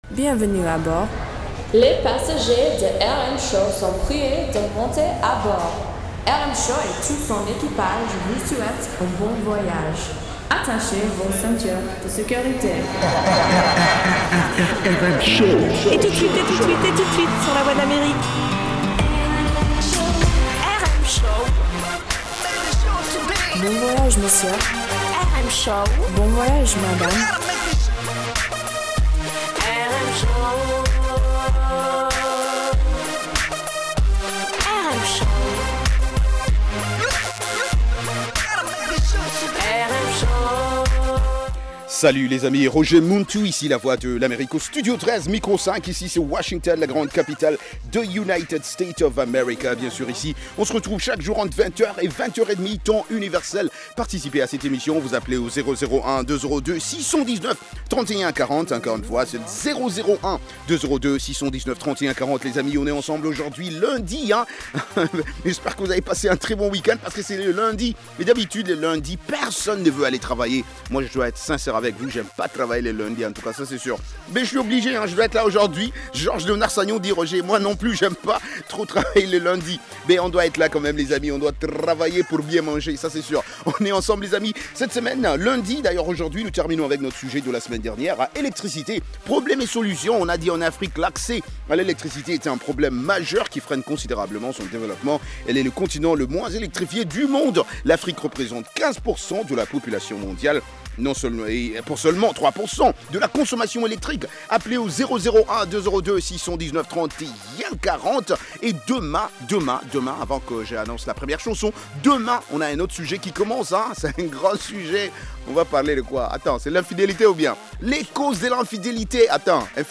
Palmarès des chansons à la mode, en rapport avec les meilleures ventes de disques aux Etats-Unies